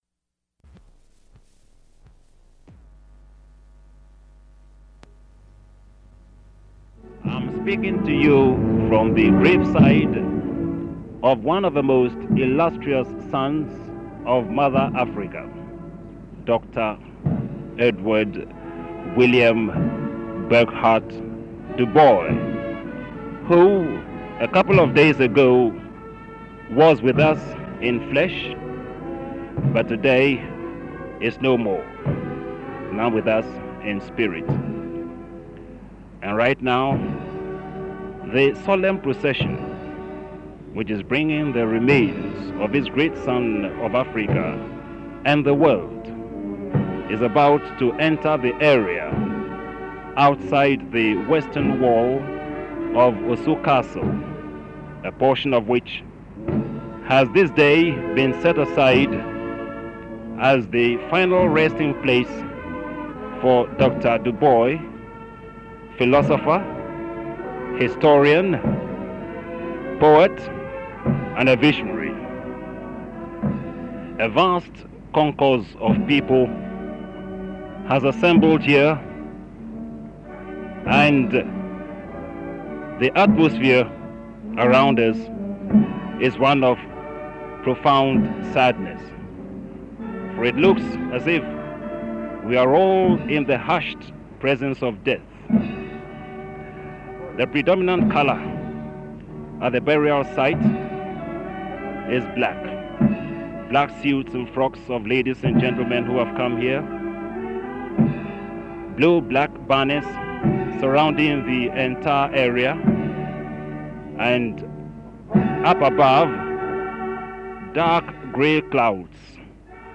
Dr. Du Bois's Burial Sermon